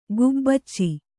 ♪ gubbacci